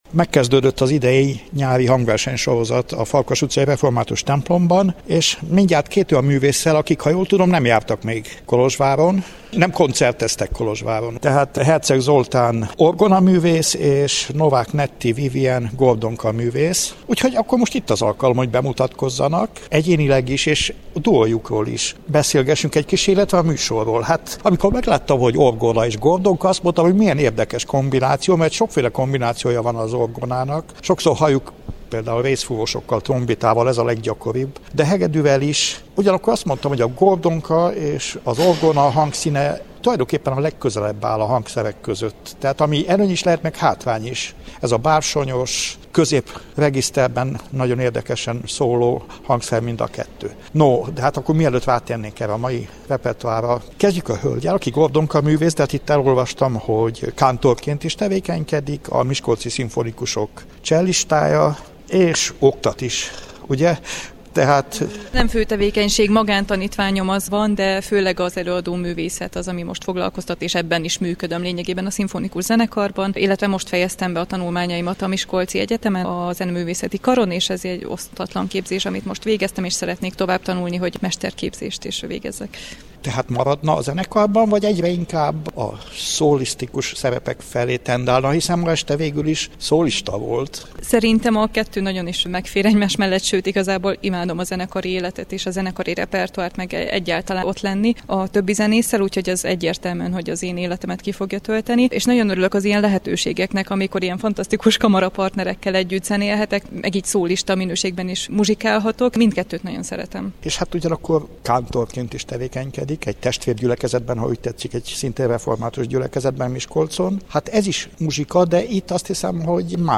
A Farkas utcai református templomban szerdánként zajló nyári hangversenysorozat nyitókoncertjén jártunk.